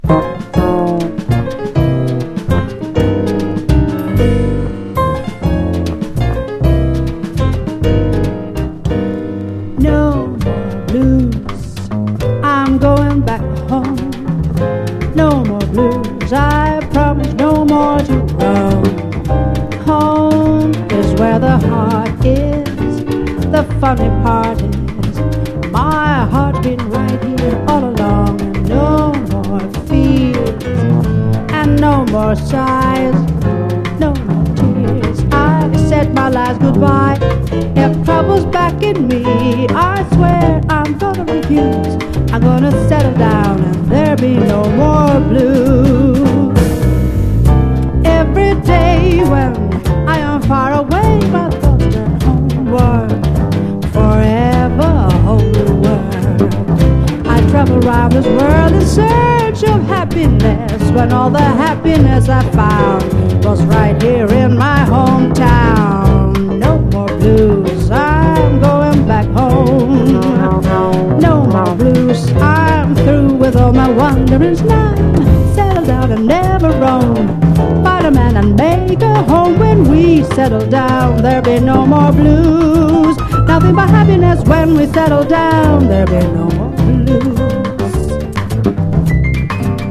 ライブラリー好きも必聴のファンキーで洒脱なラテン・ラウンジ！